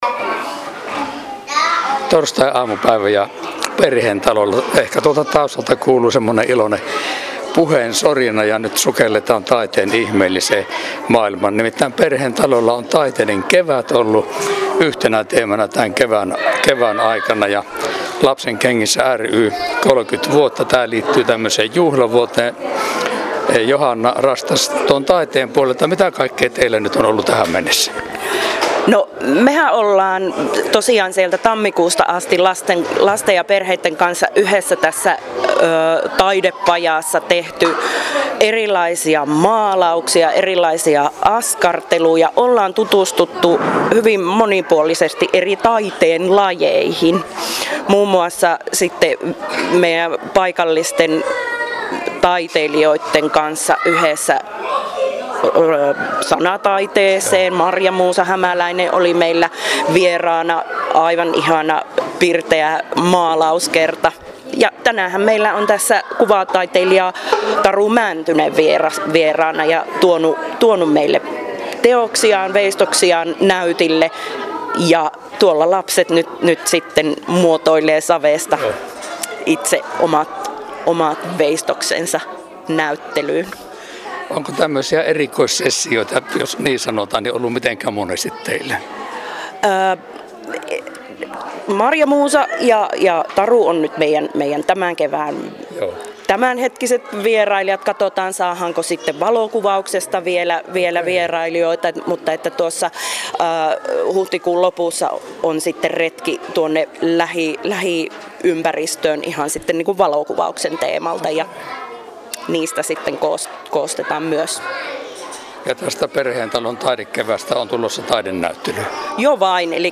Äänihaastattelussa